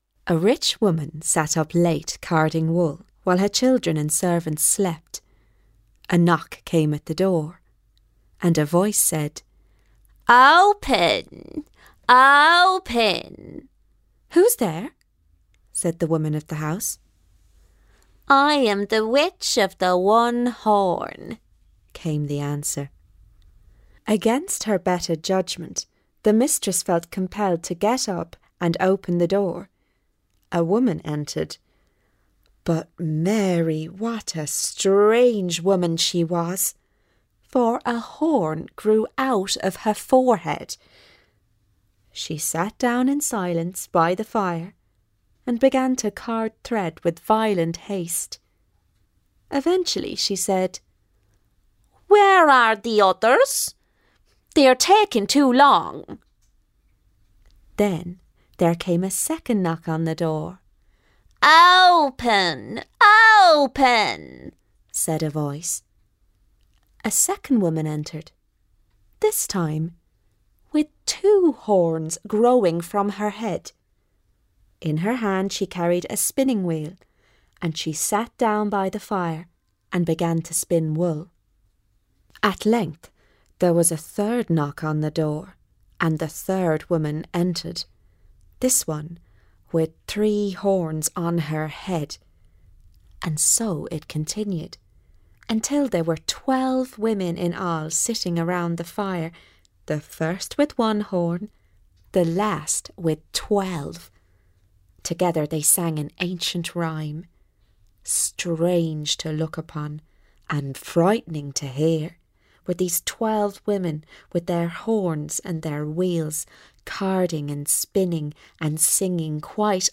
a spooky irish story.mp3